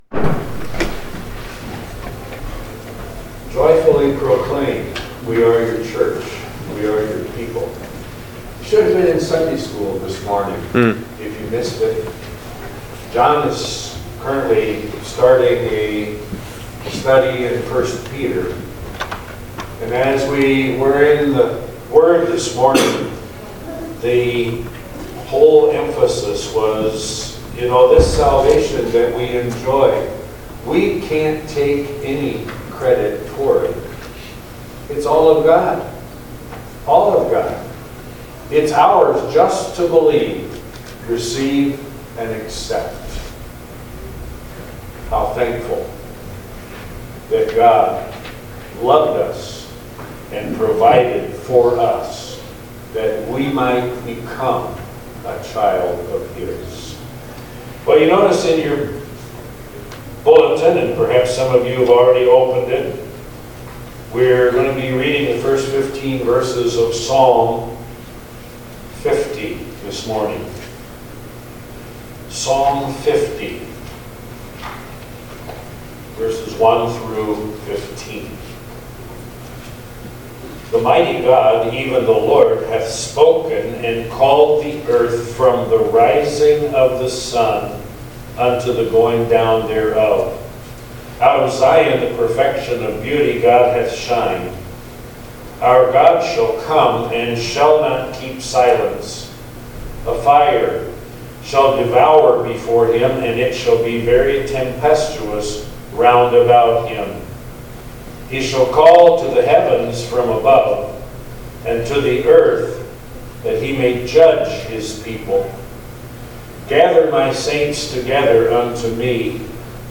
Guest Speaker
Sunday Morning Service